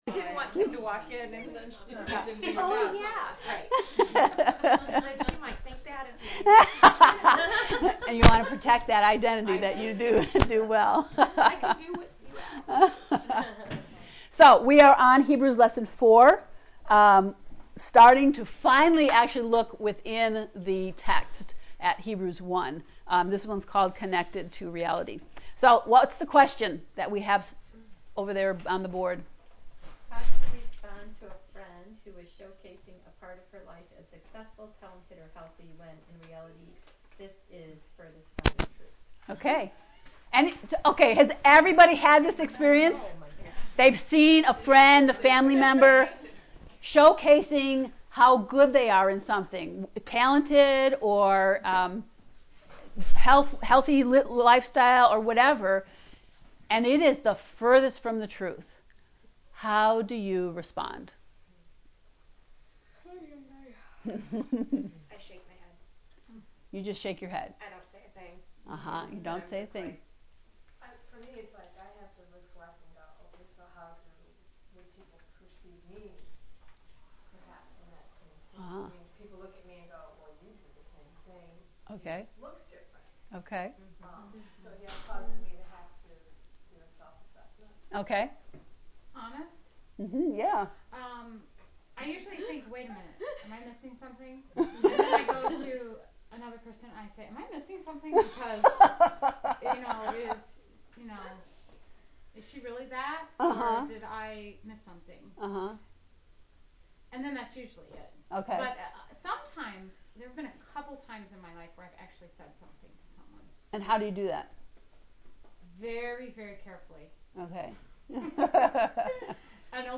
heb-lecture-4.mp3